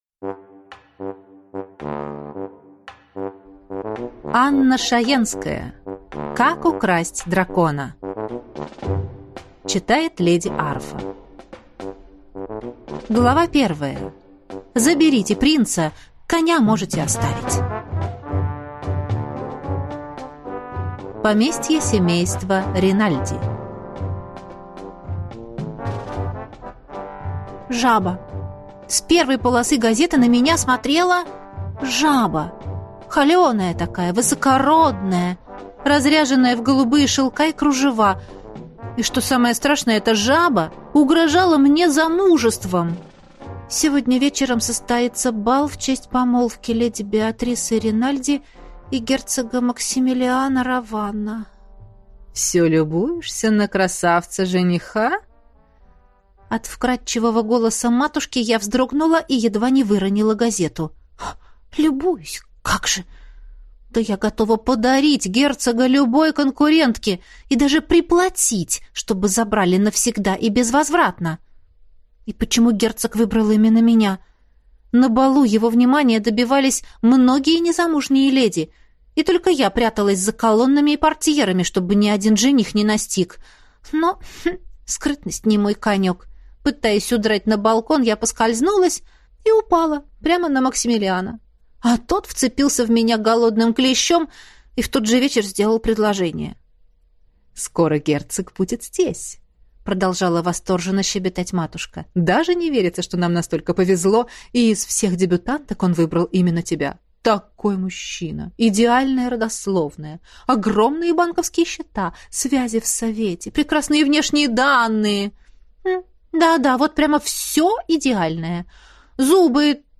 Аудиокнига Как украсть дракона | Библиотека аудиокниг